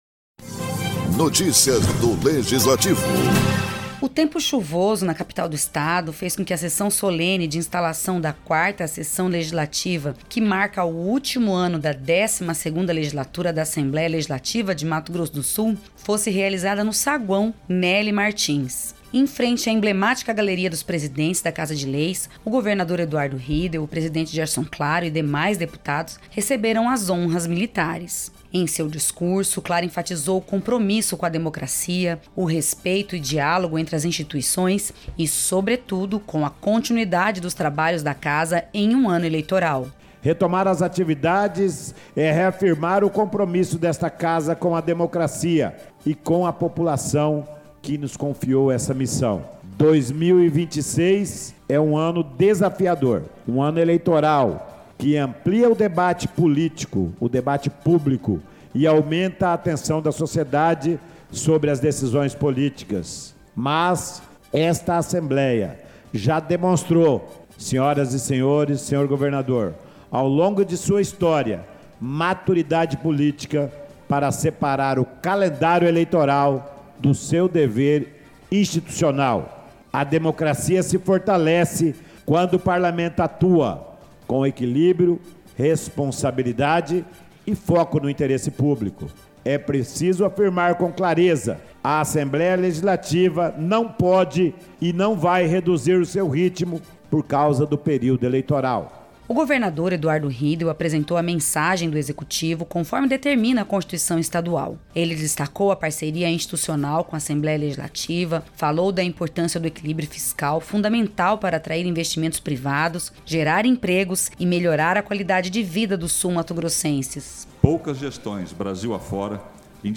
Em seu discurso, o presidente Gerson Claro do PP, enfatizou o compromisso com a democracia, o respeito e diálogo entre as instituições e, sobretudo, com a continuidade dos trabalhos da Casa em um ano eleitoral.